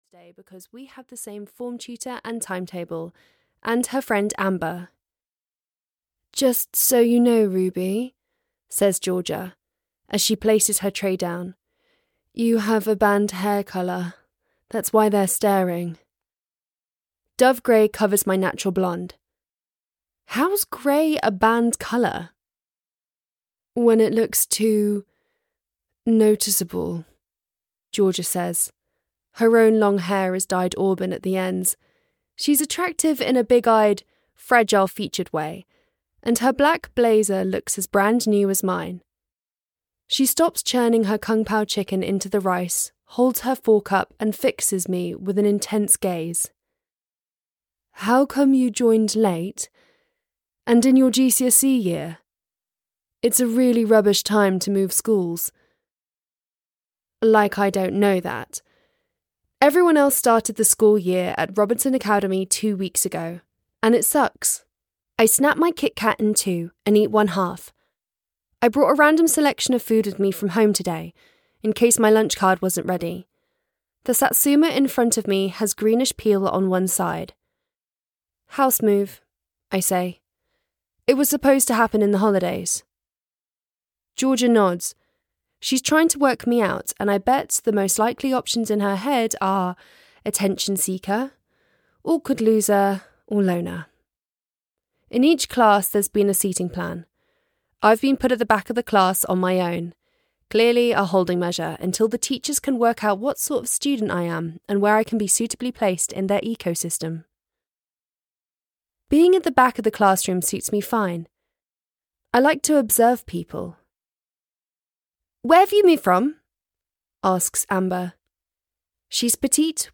I Know You Did It (EN) audiokniha
Ukázka z knihy